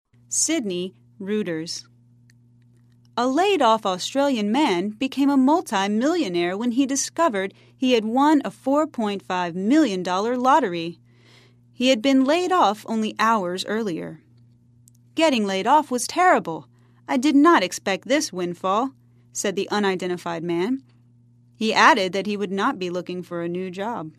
在线英语听力室赖世雄英语新闻听力通 第36期:一夜之间变富翁的听力文件下载,本栏目网络全球各类趣味新闻，并为大家提供原声朗读与对应双语字幕，篇幅虽然精短，词汇量却足够丰富，是各层次英语学习者学习实用听力、口语的精品资源。